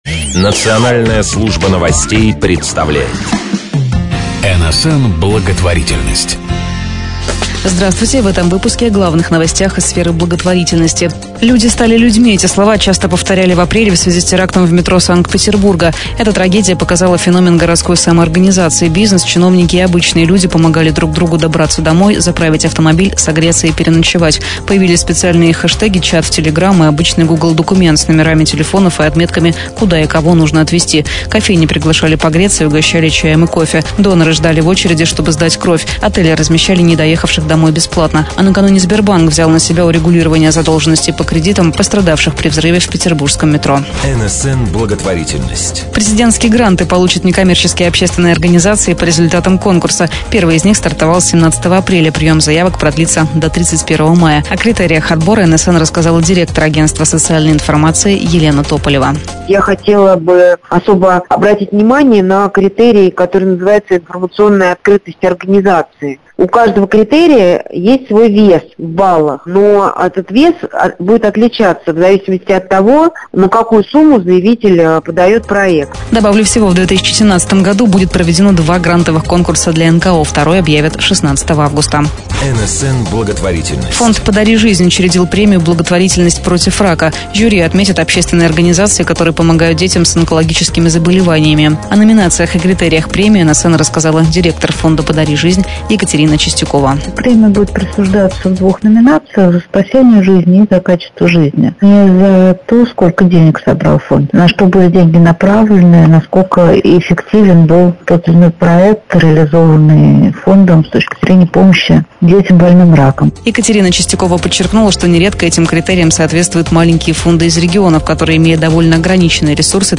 Специальный информационный выпуск содержит топ-5 новостей из сферы благотворительности.
Выпуски новостей выходят ежемесячно по средам в 19.00 для слушателей радиостанций «Наше Радио», ROCK FM, Best FM, Radio JAZZ.